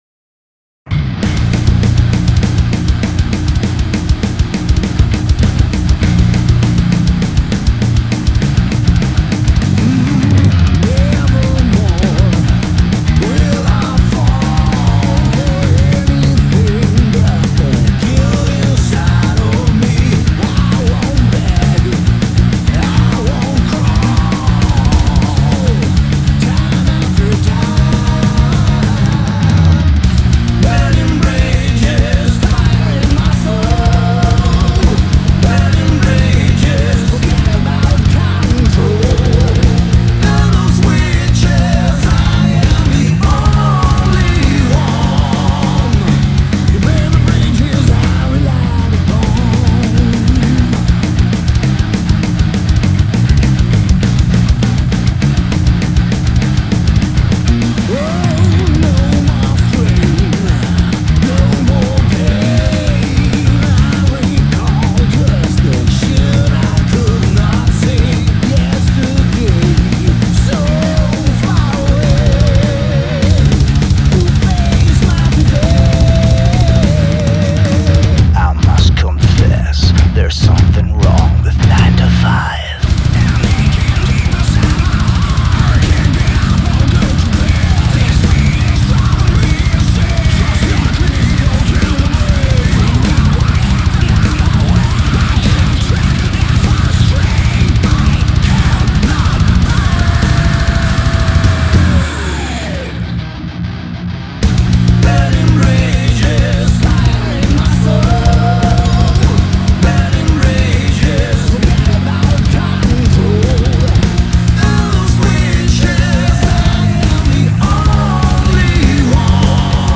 This gave it (I hope) a fuller, more balanced sound.